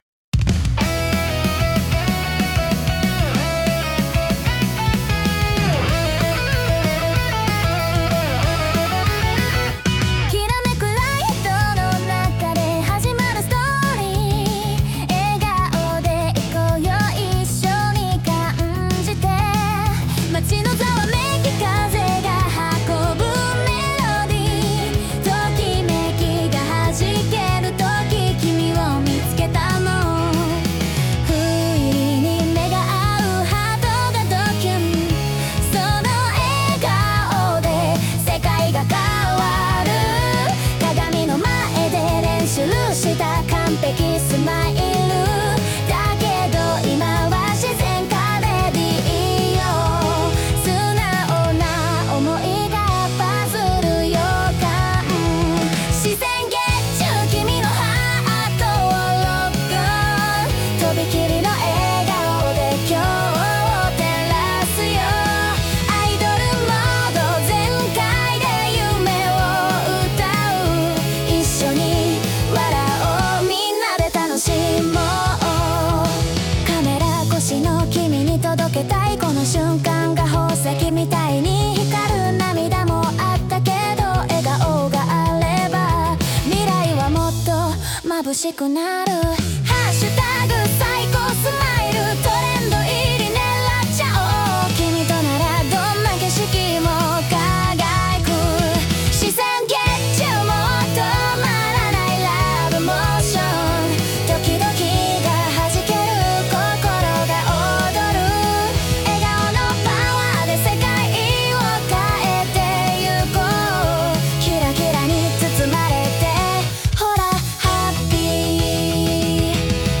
— SNSで話題になること間違いなしのキュートなポップナンバー！